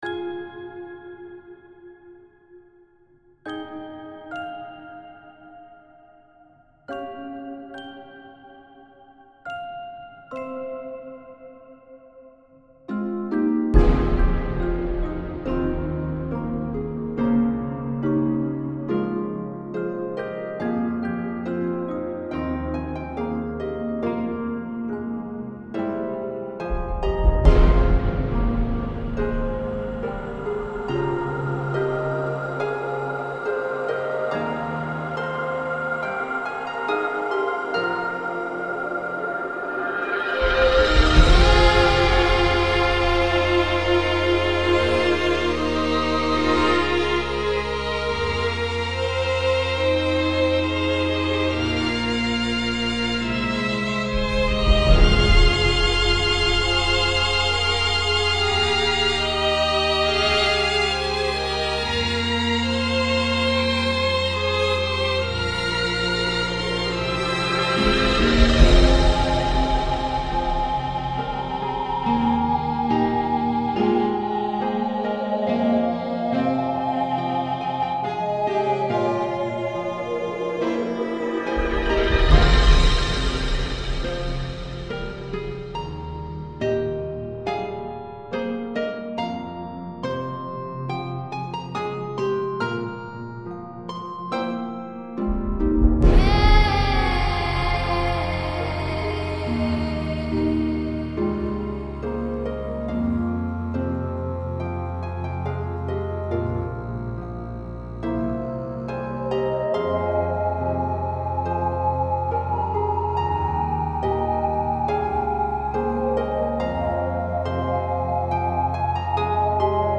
tambient slow peace